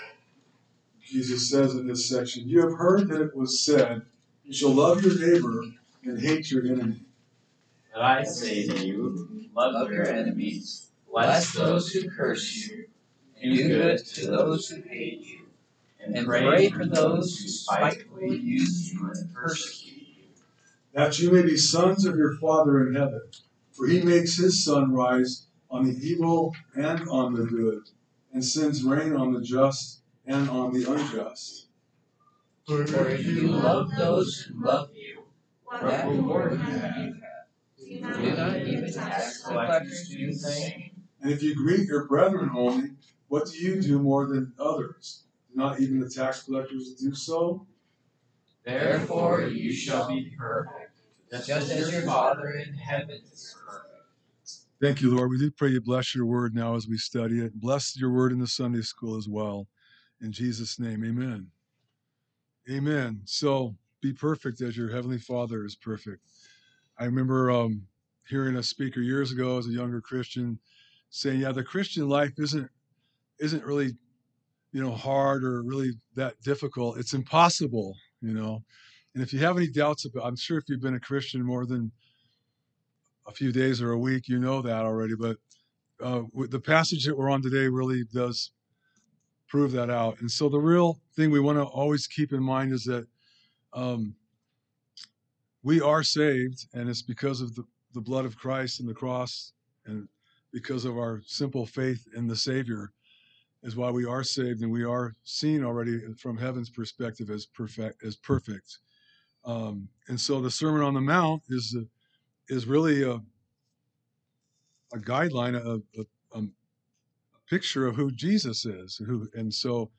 Message: "Love Your Enemies"